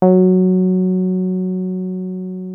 303 F#3 5.wav